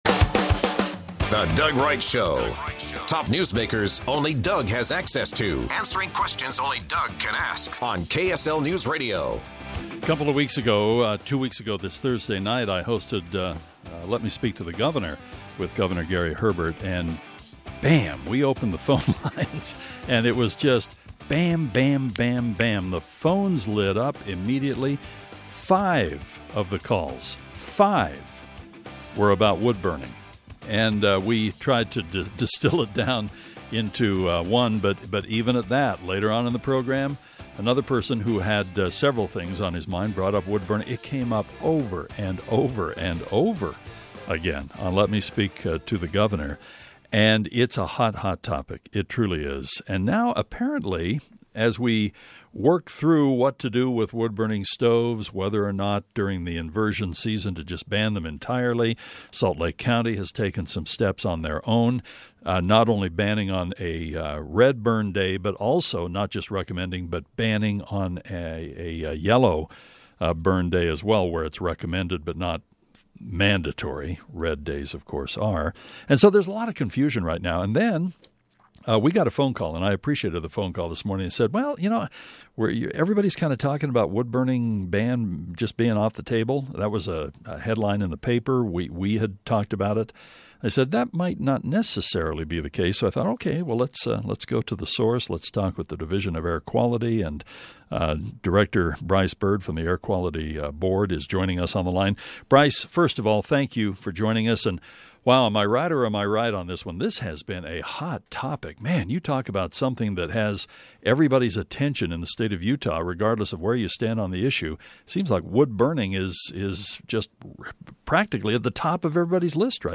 After hearing news the the wood burning ban proposal for SLC may get dropped we contacted Bryce Bird the Air Quality Board Agency Director about the feedback they've been getting and what may happen.